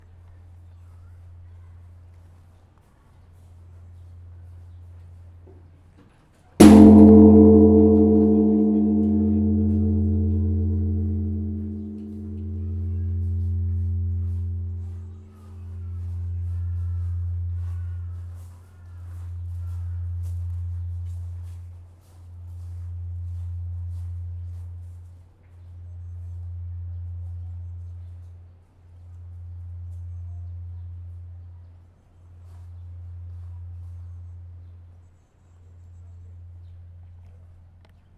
buddhsim monk is playing a gong in zen buddhism
bang bell blacksmith buddhism clang ding gong hammer sound effect free sound royalty free Sound Effects